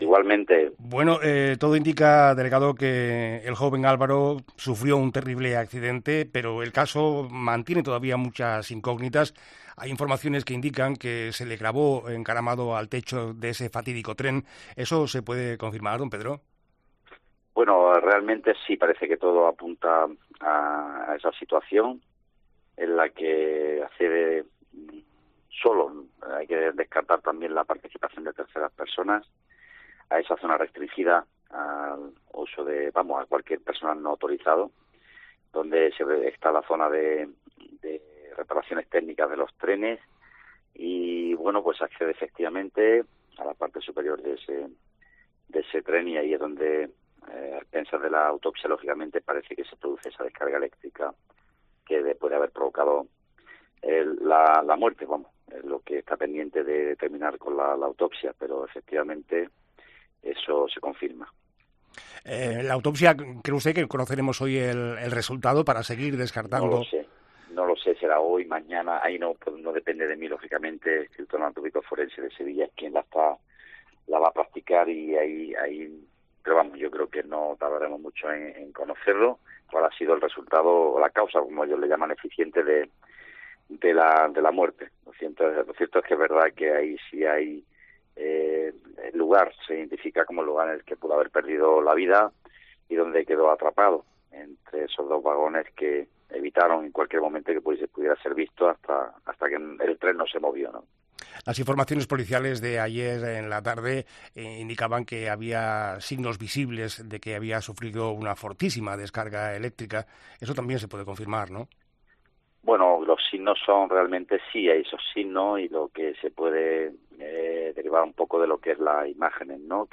entrevista al delegado del Gobierno